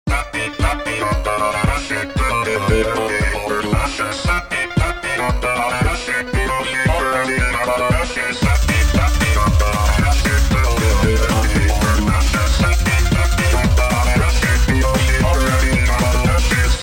Chipspeech vocals